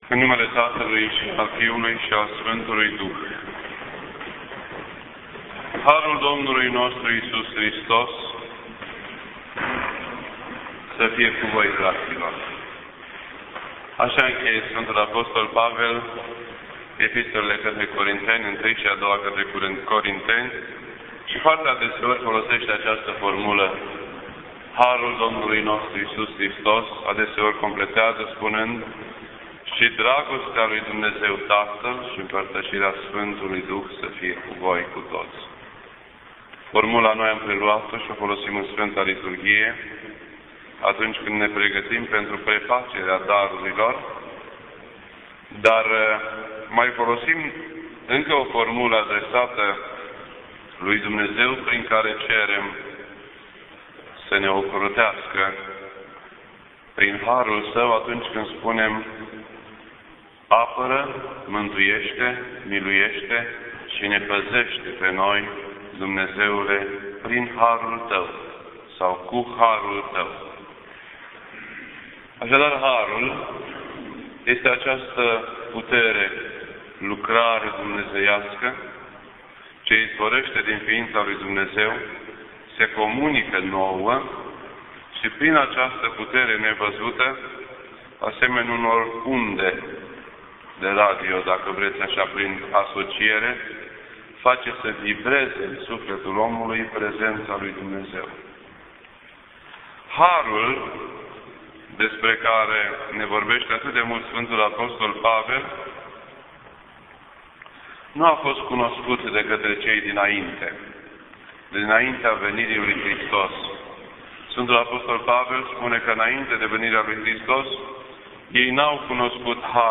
This entry was posted on Sunday, September 11th, 2011 at 7:19 PM and is filed under Predici ortodoxe in format audio.